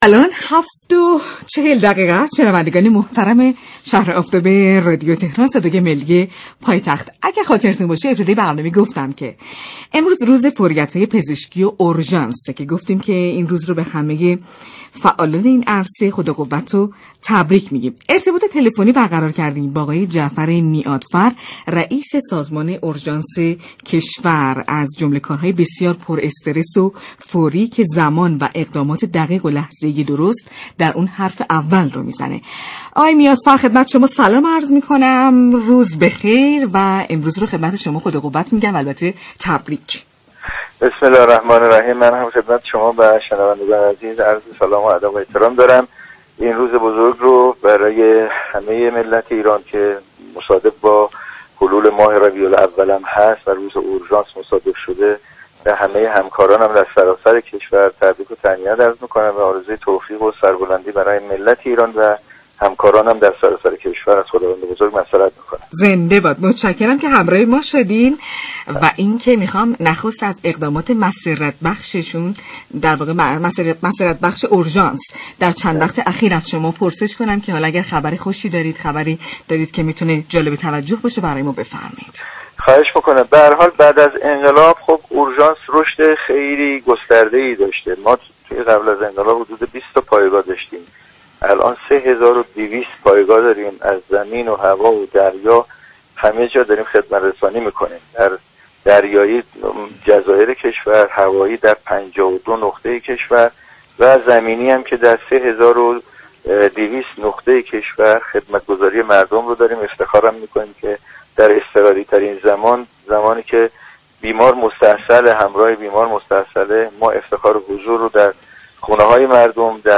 3 هزار و 200 پایگاه اورژانس به مردم خدمت‌رسانی می‌كنند به گزارش پایگاه اطلاع رسانی رادیو تهران، جعفر میعادفر رئیس سازمان اورژانس كشور به مناسبت 26 شهریورماه روز اورژانس و فوریت‌های پزشكی در گفت و گو با «شهر آفتاب» اظهار داشت: قبل از انقلاب 20 پایگاه اورژانس در كشور داشتیم ولی امروز 3 هزار و 200 پایگاه اورژانس داریم؛ این پایگاه‌ها به صورت زمینی، دریایی و هوایی به مردم خدمت‌رسانی می‌كنند.